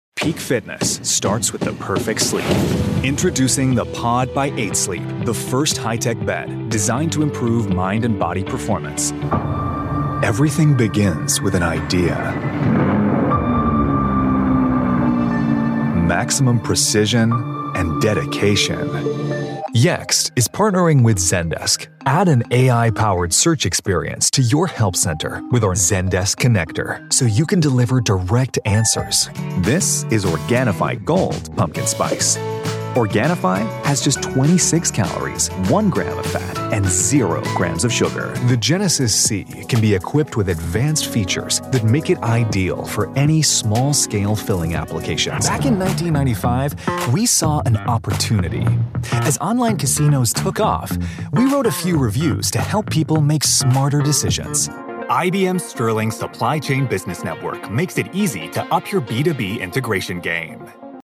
Sometimes snarky. Often Polished. Always authentic VO.
US English Demo